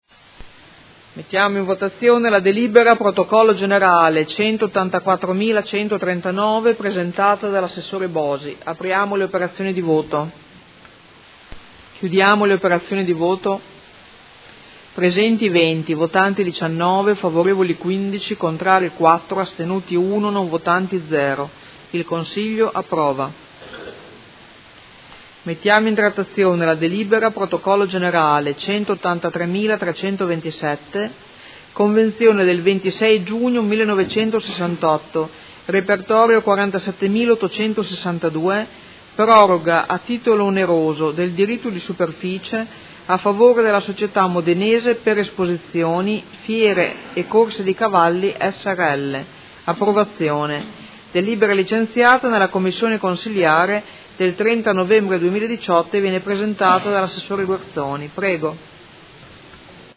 Seduta del 13/12/2018. Mette ai voti proposta di deliberazione: Razionalizzazione periodica delle partecipazioni societarie del Comune di Modena